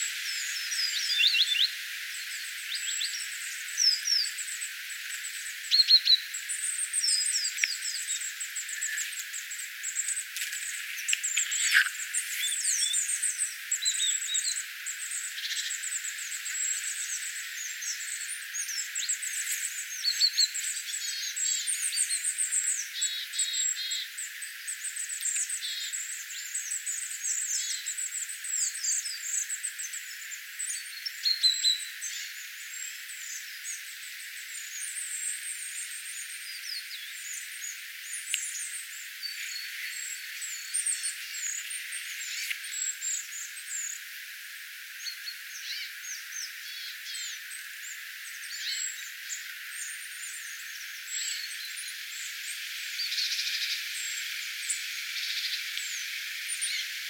pikkulintuparvi, aktiivisia hippiäisiä
pikkulintuparvi_aktiivisia_hippiaisia.mp3